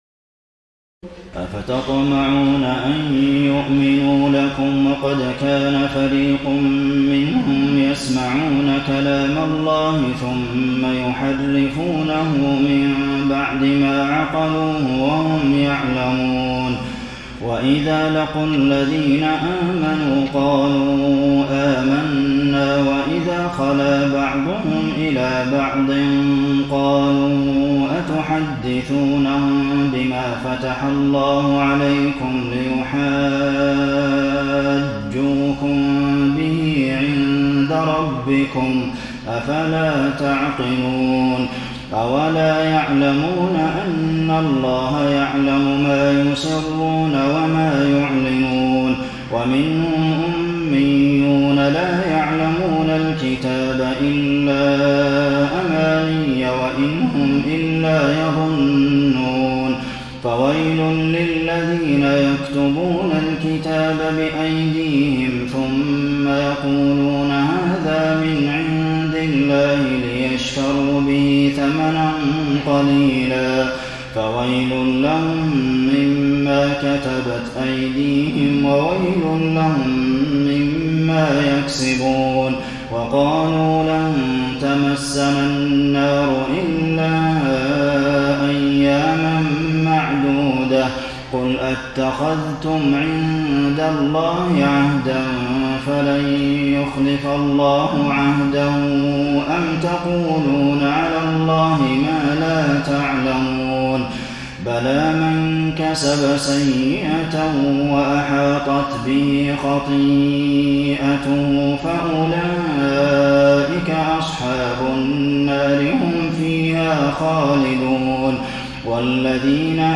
تراويح الليلة الأولى رمضان 1433هـ من سورة البقرة (75-141) Taraweeh 1st night Ramadan 1433 H from Surah Al-Baqara > تراويح الحرم النبوي عام 1433 🕌 > التراويح - تلاوات الحرمين